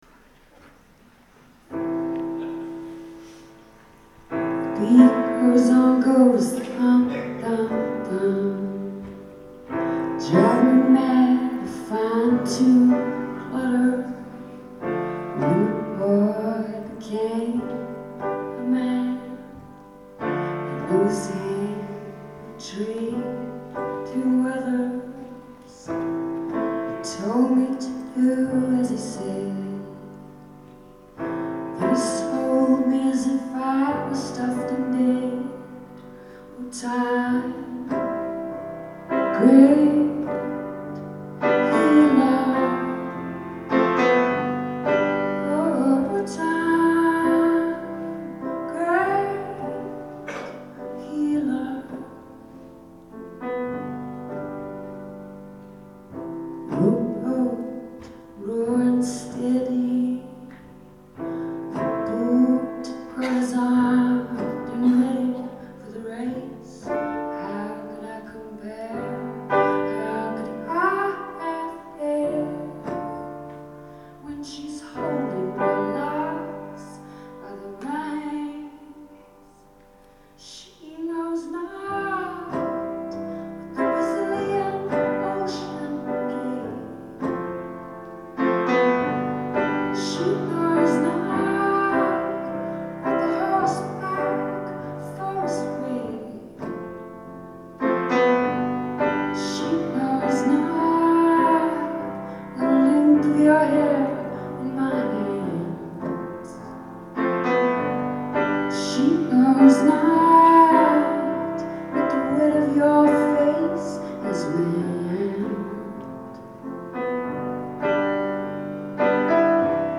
Live at the Museum of Fine Arts Boston